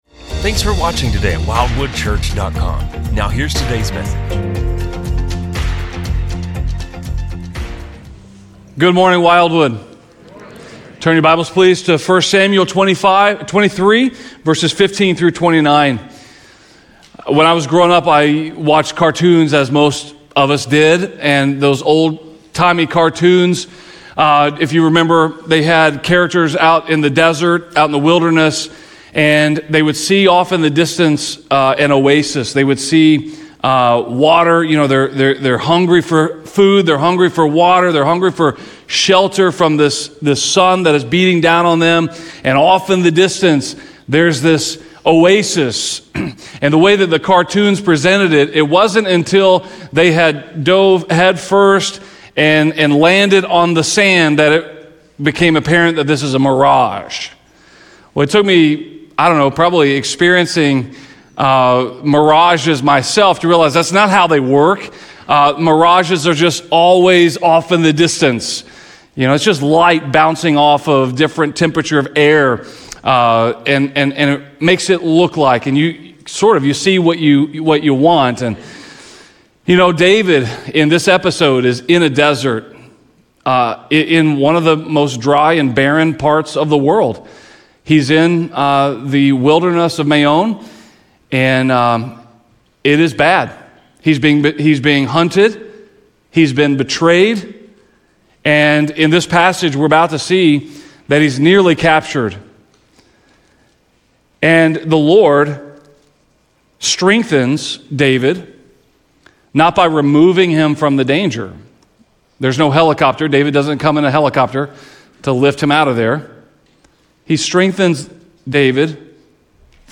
This sermon explores how Jonathan strengthened David in the Lord, how God’s promises anchored him in present danger, and how the Lord delivered him to the “Rock of Escape.” Ultimately, the passage points to Jesus—the greater Friend, the true Promise, and our everlasting Refuge in every wilderness.